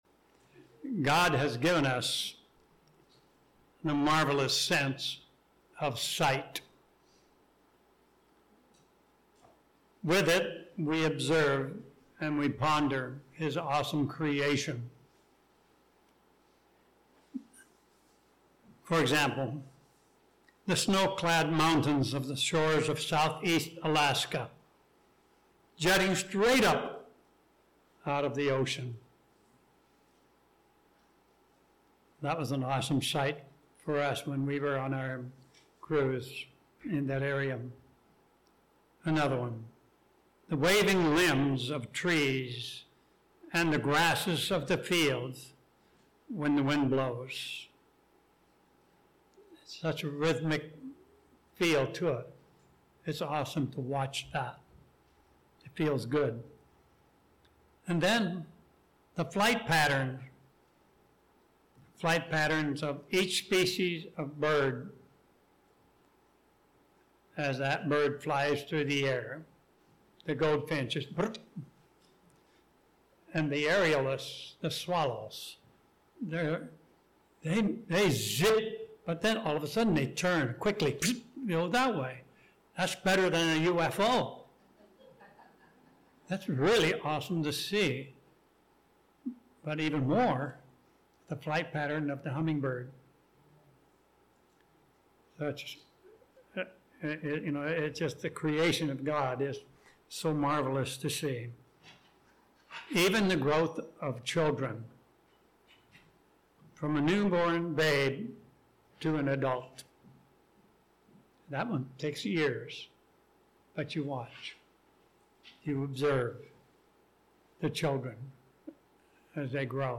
Spiritually, we are commanded to "watch." In this sermon we'll explore the "How" and the "What" of understanding Christ's command to be actively watching.